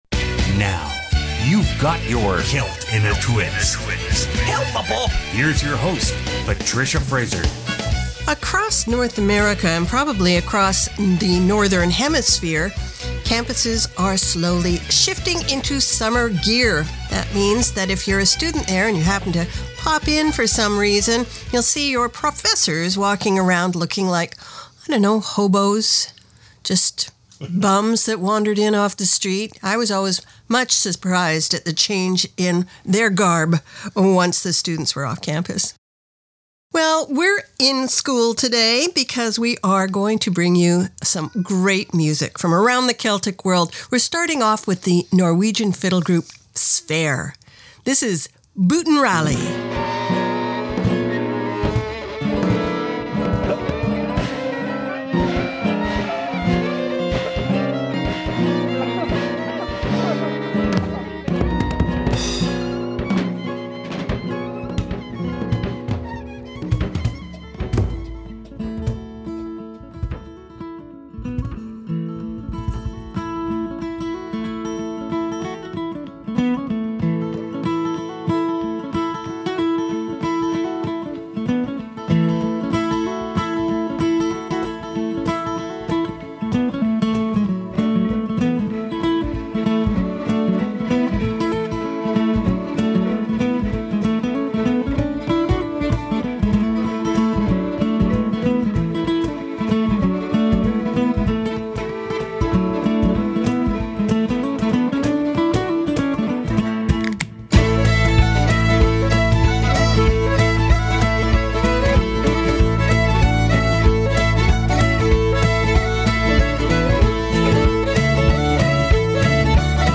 Canada's Contemporary Celtic Hour